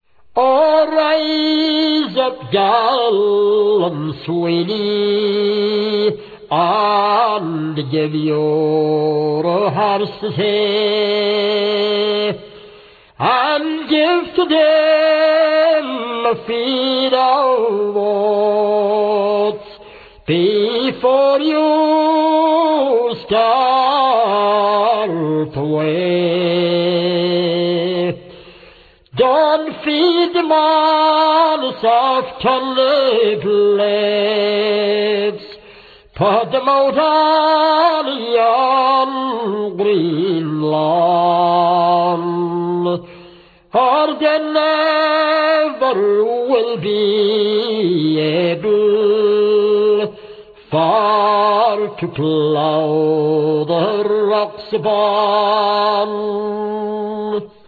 Both show comparatively restrained use of decoration.
Minor quibbles: the first note of The Rocks of Bawn has been too closely trimmed in the editing; and the tiny pale blue text on the insert is a pain to read!